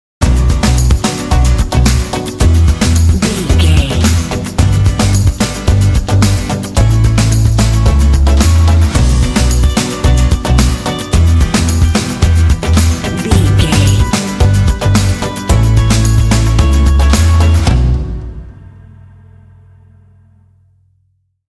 Aeolian/Minor
cool
energetic
motivational
dramatic
synthesiser
drums
acoustic guitar
bass guitar
electric guitar
rock
alternative rock